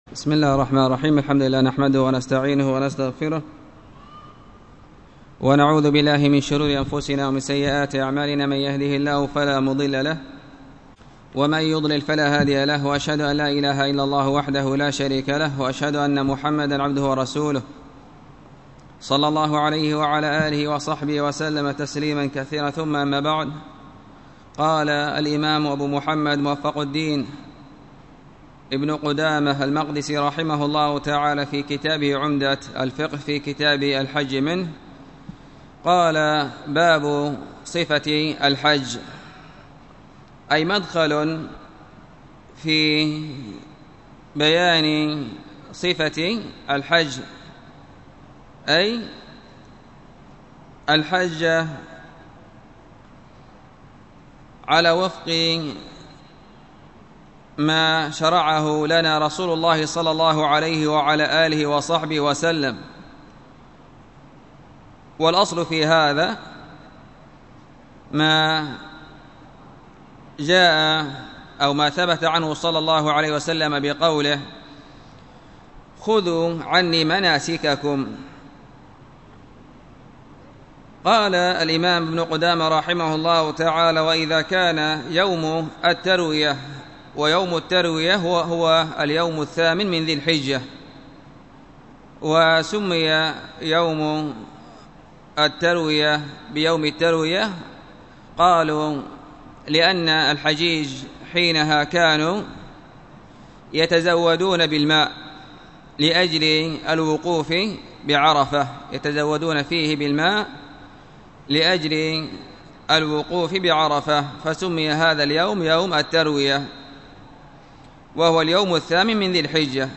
الدرس في كتاب الطهارة 7